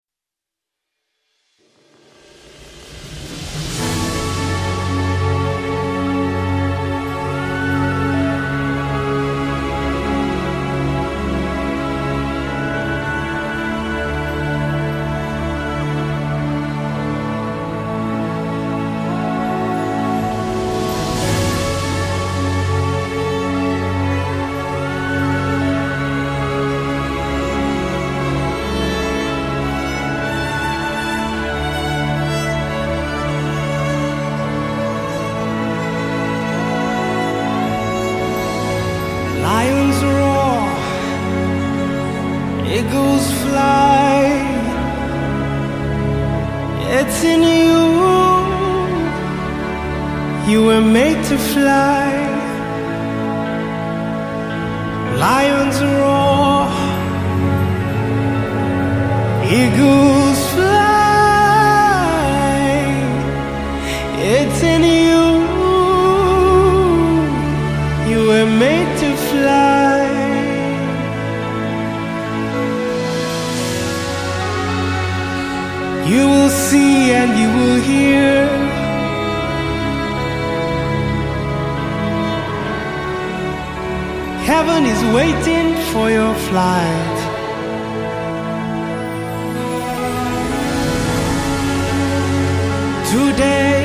GOSPEL MUSICS